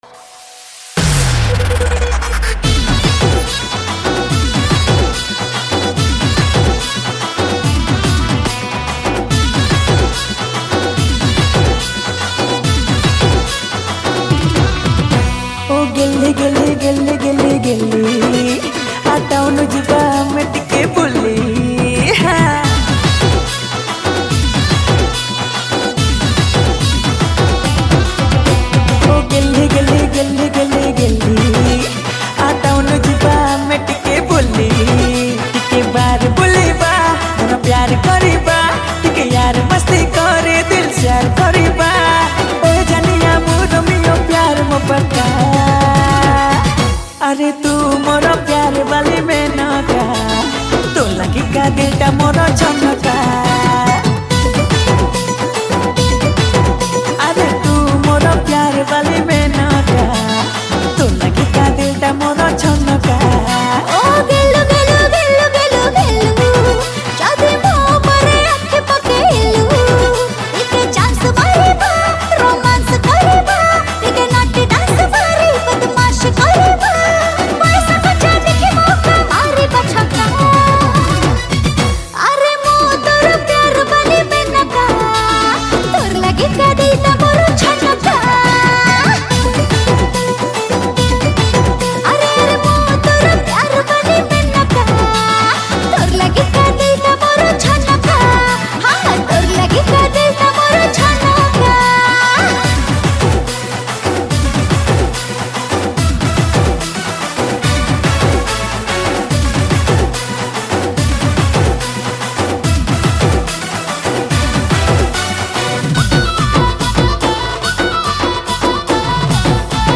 Studio Version